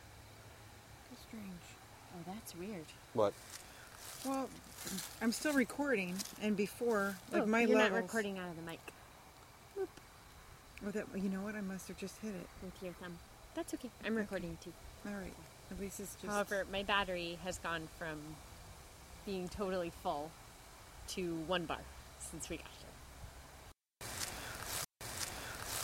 After I ask "what",  we catch a quick faint EVP of a male voice saying "Yeah",  like he agrees something odd is going on!   Played twice at the end